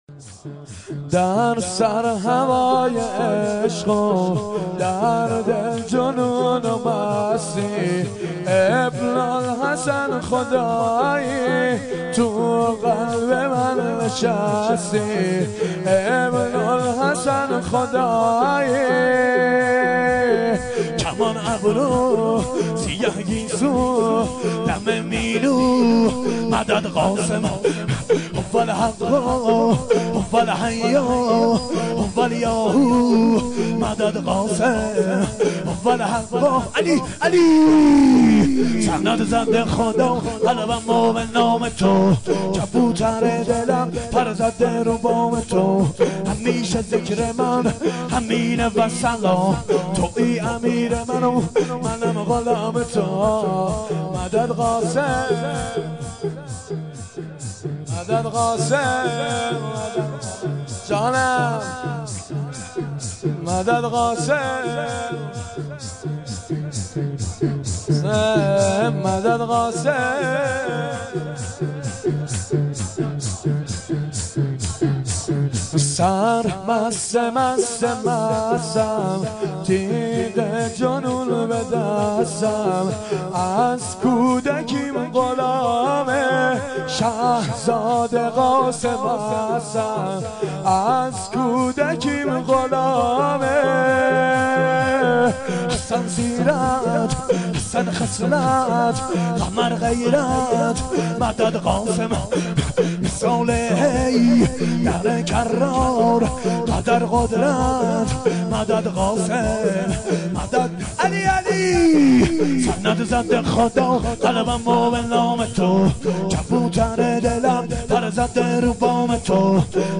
شور
شب ششم محرم ۱۴۴۱